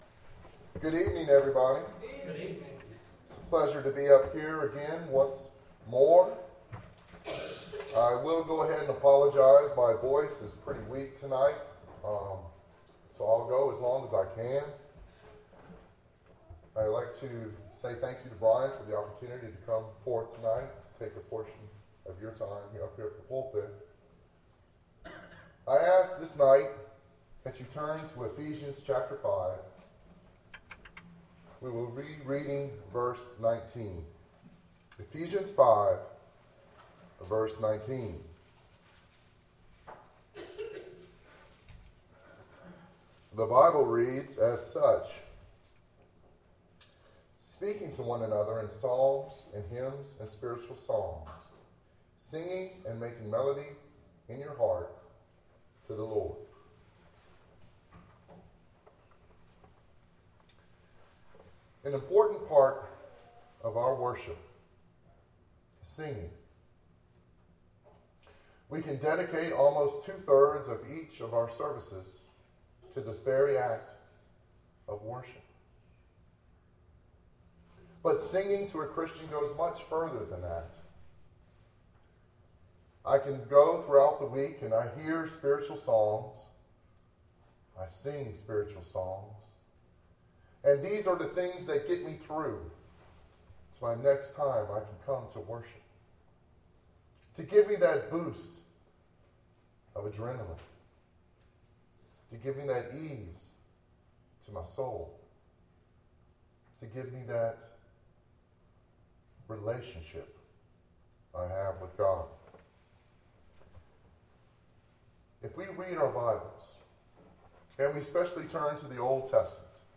Ephesians 5:19 Service Type: PM Worship Bible Text